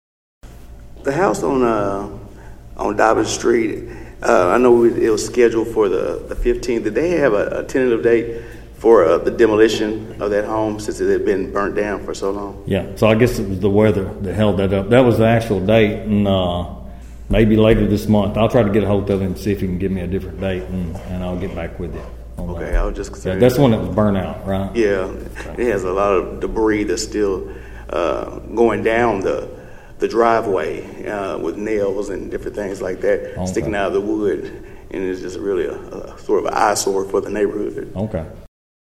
During the latest City Council meeting, Councilman Patrick Smith asked City Manager Johnny McTurner about a scheduled demolition on Dobbins Street.(AUDIO)